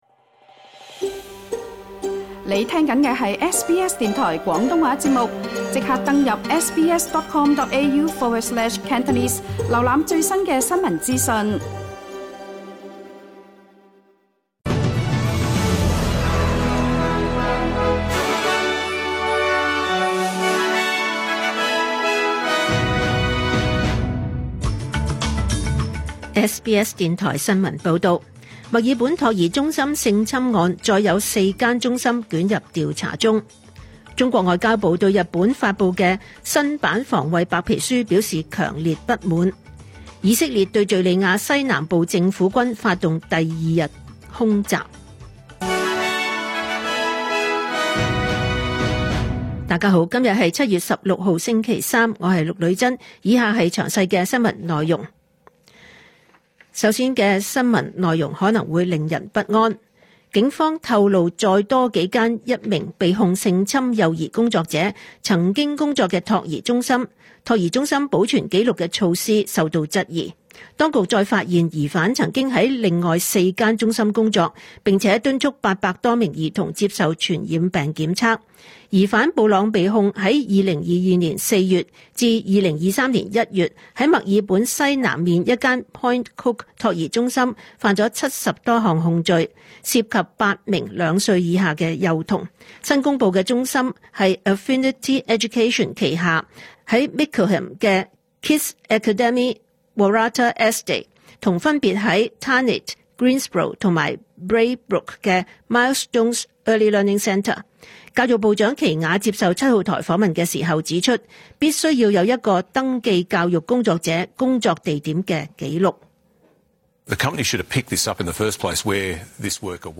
2025 年 7 月 16 日 SBS 廣東話節目詳盡早晨新聞報道。